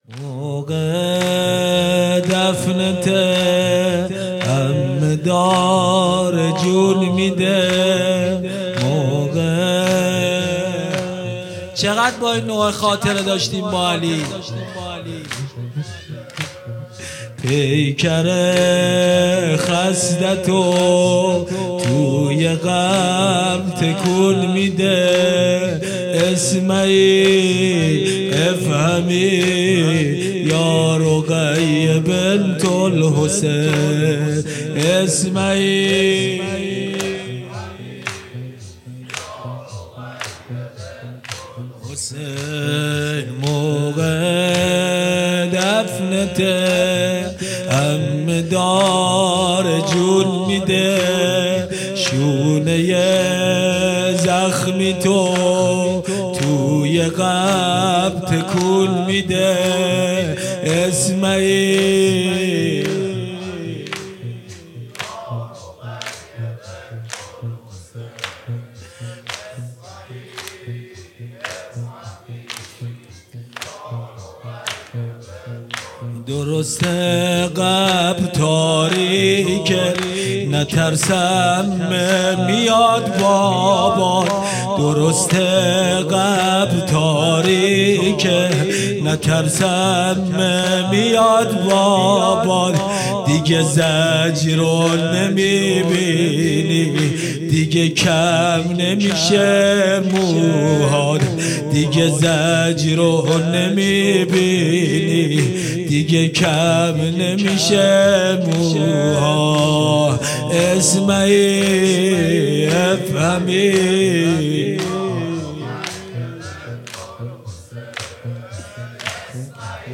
مجلس روضه هفتگی